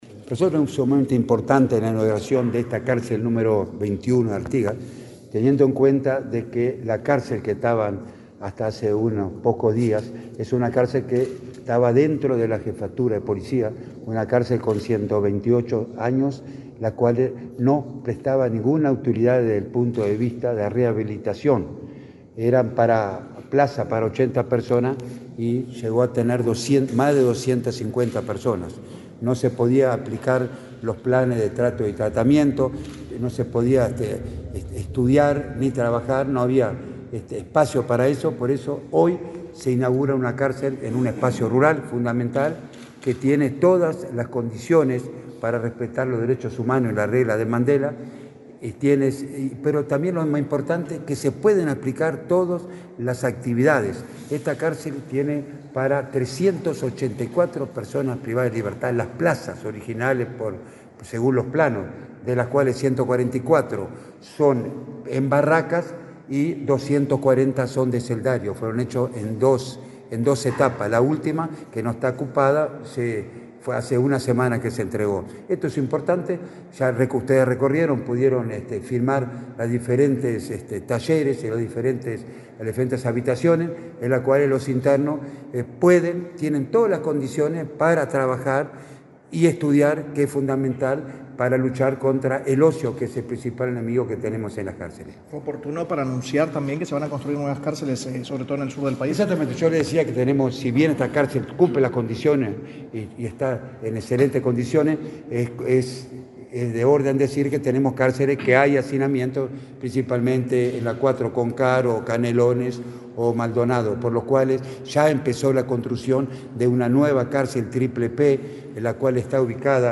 Declaraciones del director del INR, Luis Mendoza 25/09/2024 Compartir Facebook X Copiar enlace WhatsApp LinkedIn El director del Instituto Nacional de Rehabilitación, Luis Mendoza, y el ministro del Interior, Nicolás Martinelli, participaron la nueva cárcel de Artigas, en el paraje Pintado Grande de ese departamento. Luego, Mendoza dialogó con la prensa.